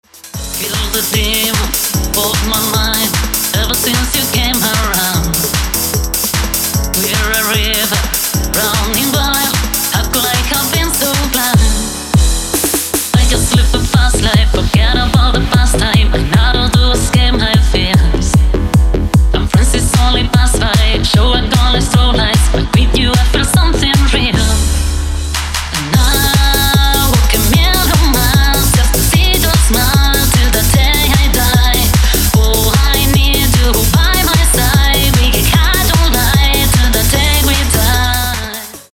Workout Mix Edit 150 bpm